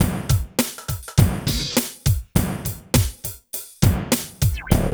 84 DRUM LP-L.wav